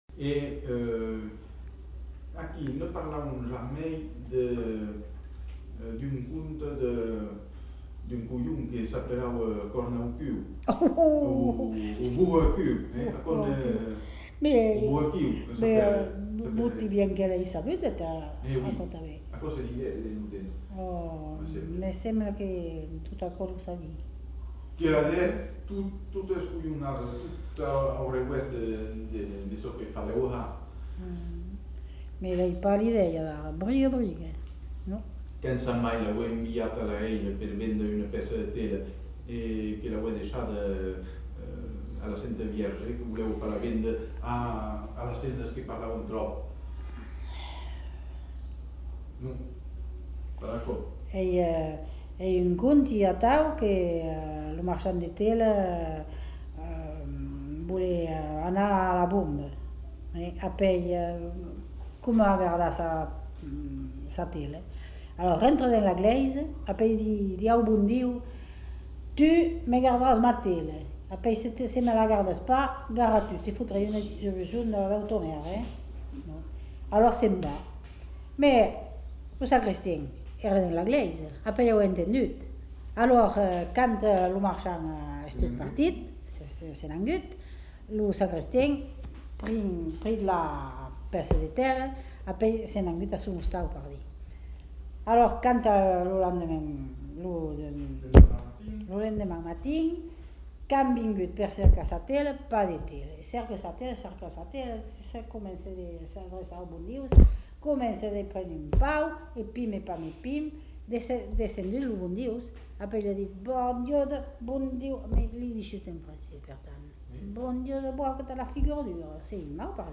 Lieu : Moulis-en-Médoc
Genre : conte-légende-récit
Effectif : 1
Type de voix : voix de femme
Production du son : parlé
Notes consultables : Récit dit en occitan mais entendu en français.